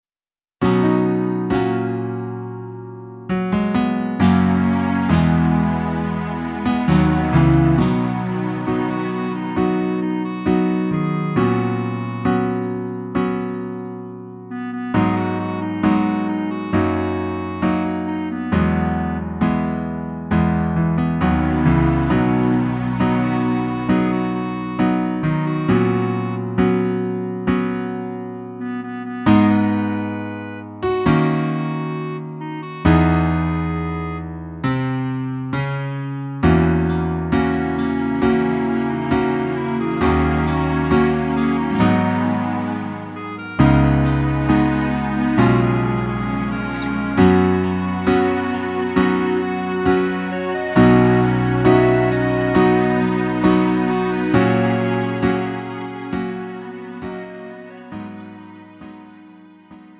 음정 -1키 3:57
장르 가요 구분 Lite MR